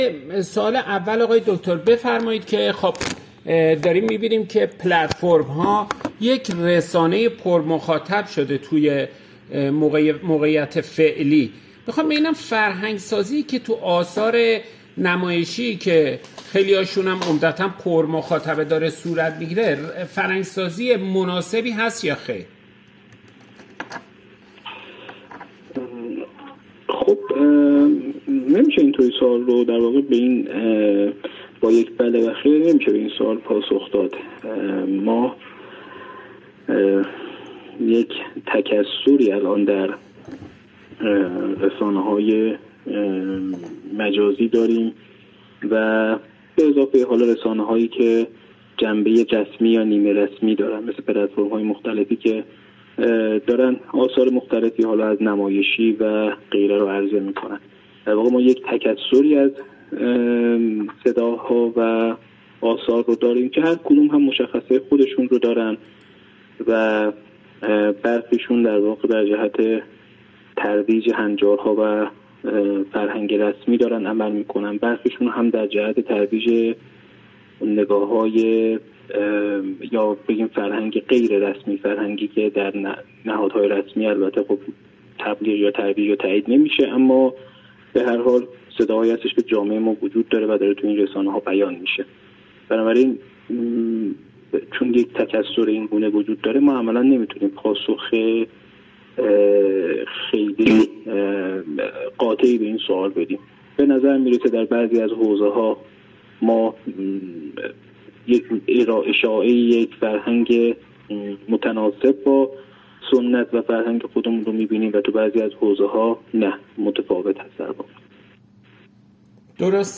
در سلسله گفت‌وگوهای ایکنا با رویکرد «شبکه خانگی، اخلاق و سبک زندگی» با اهالی سینما و تلویزیون برآنیم تا در ایکنا به آسیب‌شناسی این موضوع بپردازیم و در پایان به ارائه راهکارهای اجرایی توسط هنرمندان برسیم.